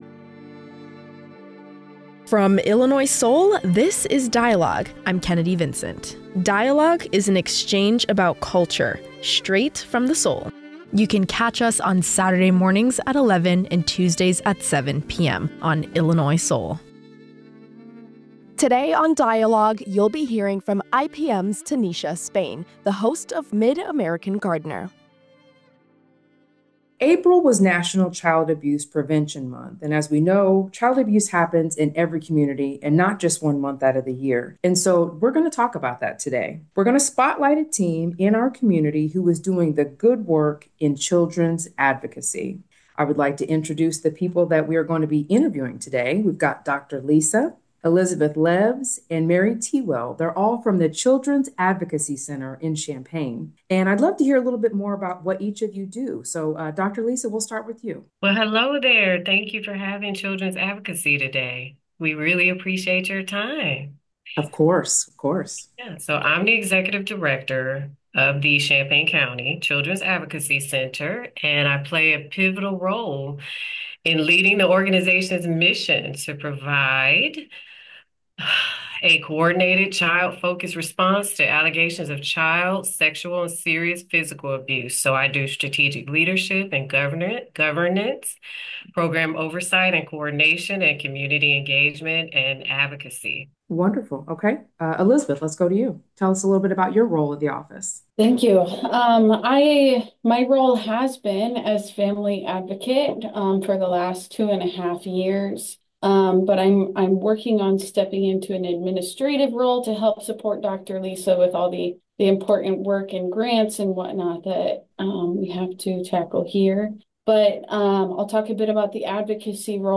Protecting children’s future: A conversation on child advocacy and mental health